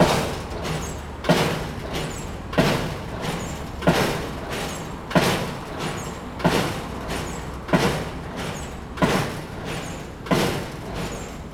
background_construction_industrial_loop.wav